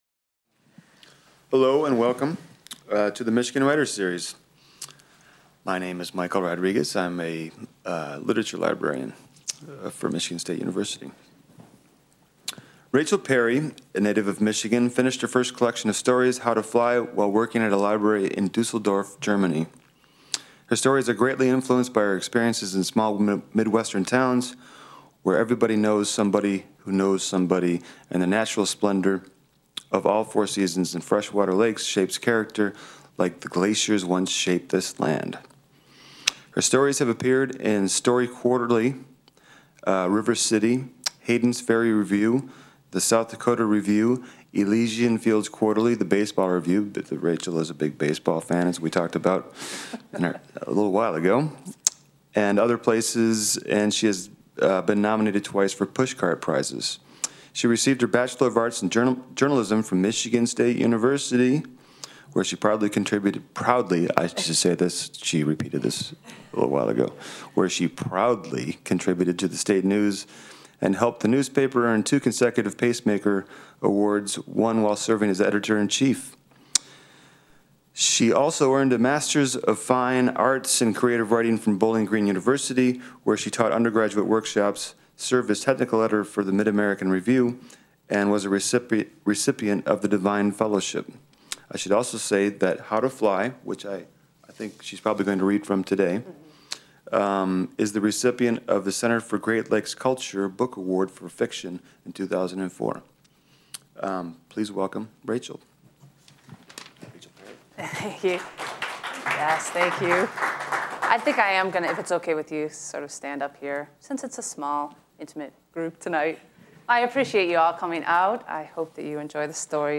Part of the MSU Libraries' Michigan Writers Series. Held in the Main Library.